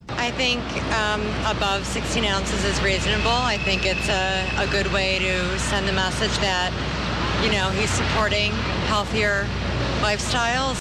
This woman, interviewed on the street in New York on Thursday, is an example.
WOMAN ON STREET IN NYC (Listen